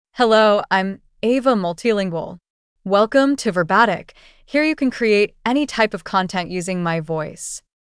FemaleEnglish (United States)
Ava MultilingualFemale English AI voice
Ava Multilingual is a female AI voice for English (United States).
Voice sample
Listen to Ava Multilingual's female English voice.